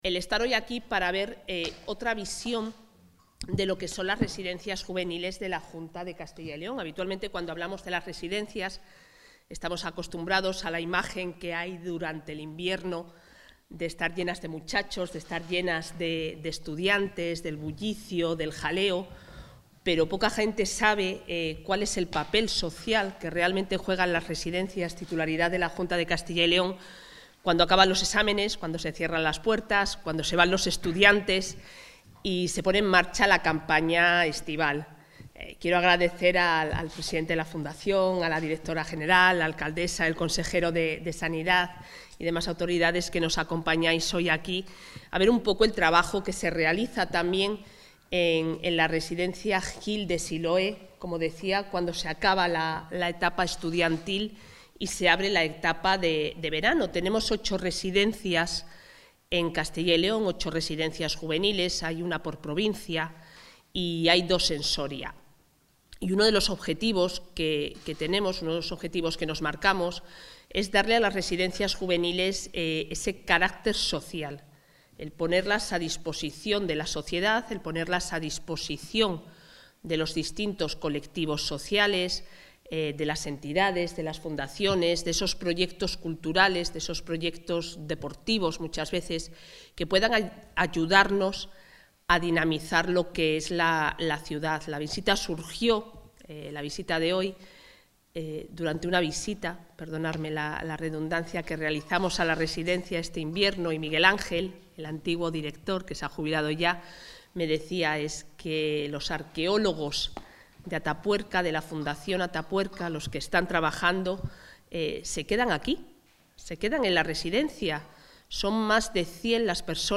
Declaraciones de la consejera.